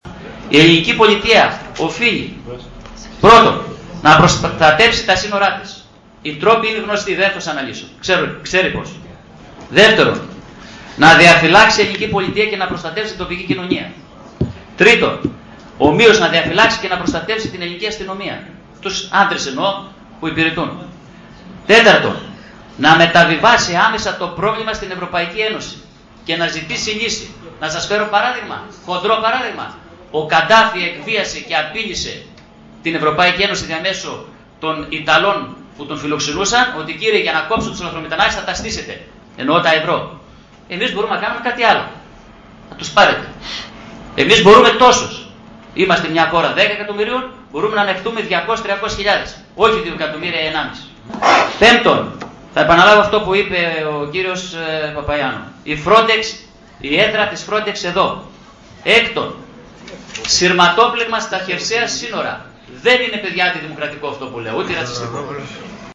Κ. Λύτρας: Τι πρέπει να γίνει για τη λαθρομετανάστευση – Δημ. Συμβ. Ορ/δας 14.09.2010